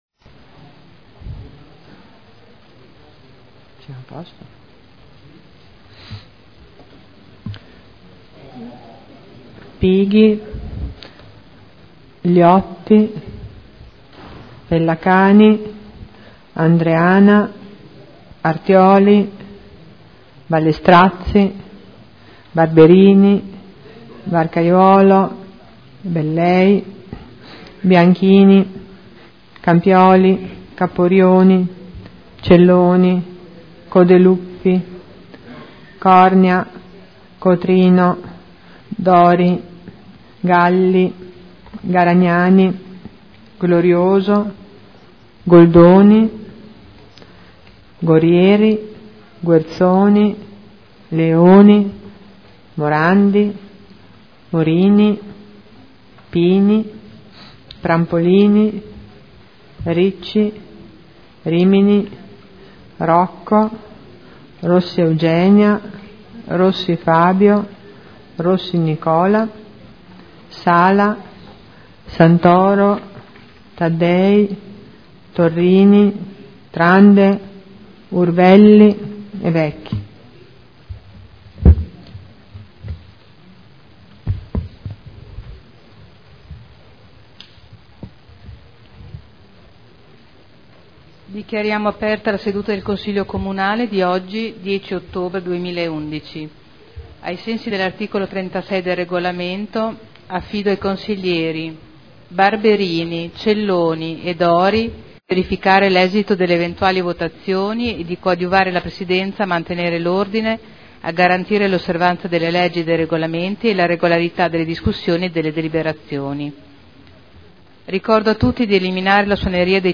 Vice Segretario generale — Sito Audio Consiglio Comunale
Seduta del 10 ottobre 2011 Apertura del Consiglio Comunale Appello